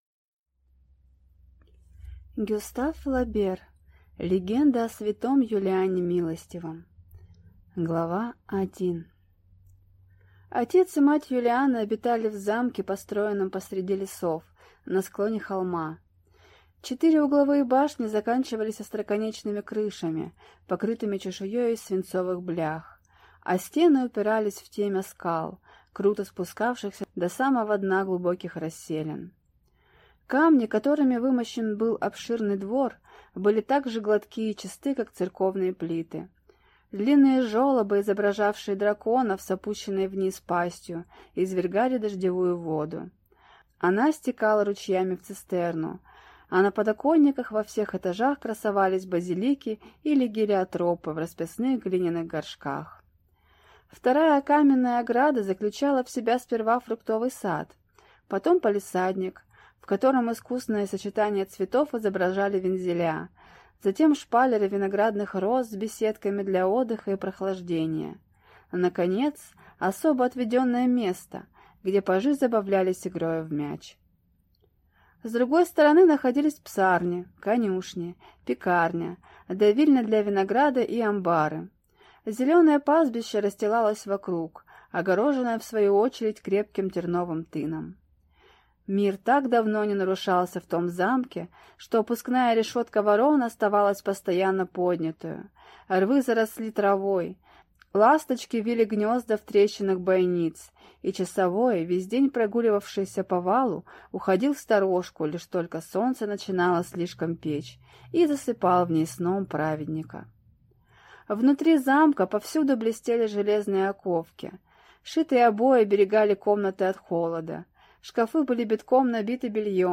Аудиокнига Легенда о св. Юлиане Милостивом | Библиотека аудиокниг